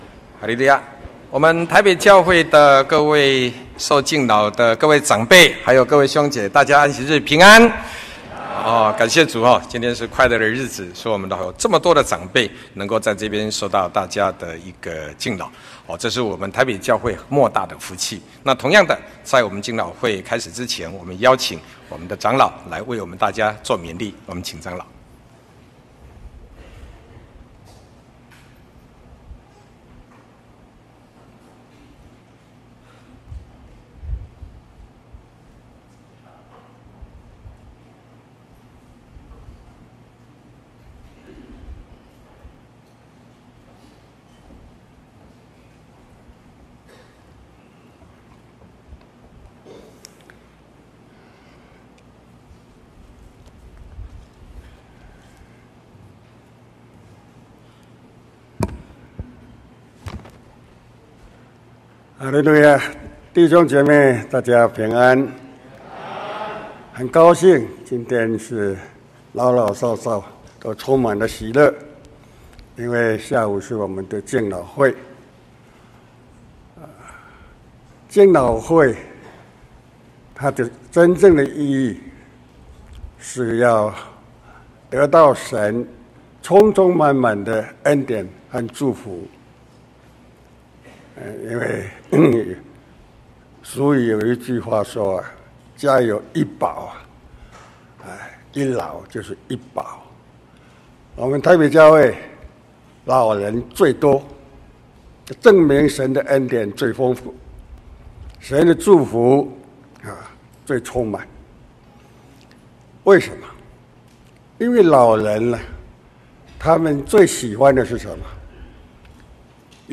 敬老會-講道錄音